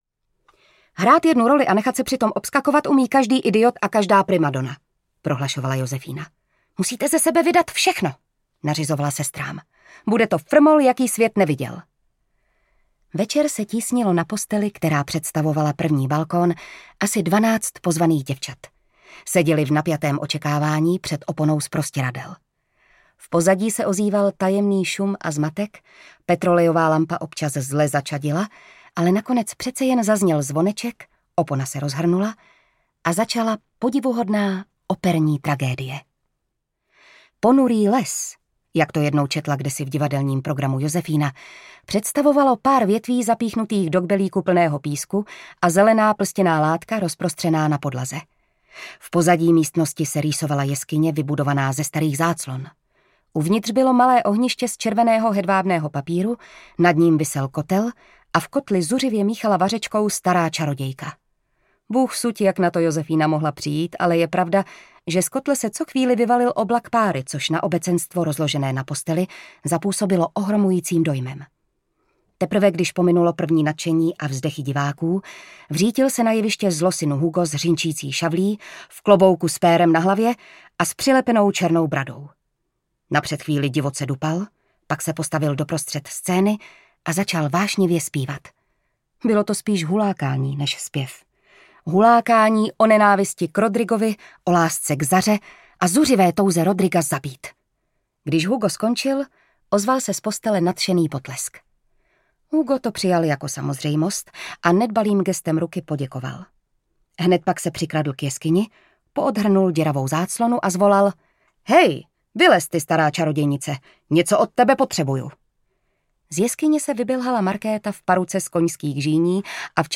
Malé ženy audiokniha
Ukázka z knihy
Vyrobilo studio Soundguru.